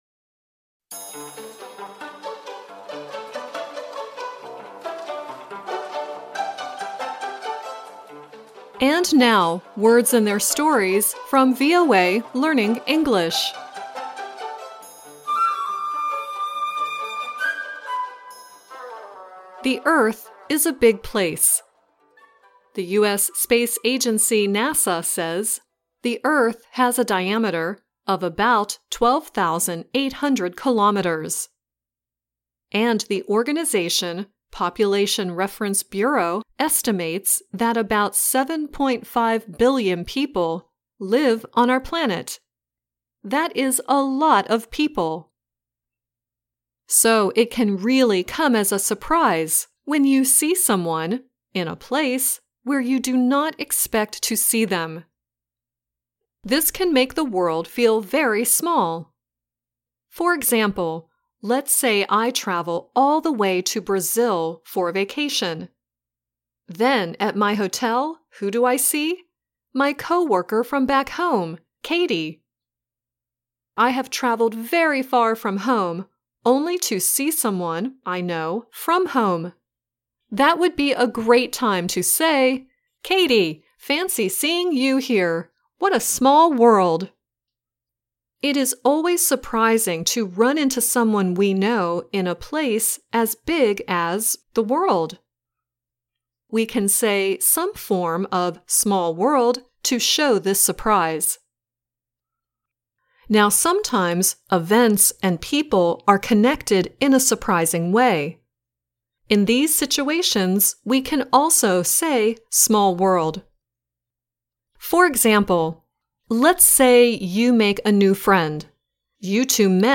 The song at the end is "It's a Small World."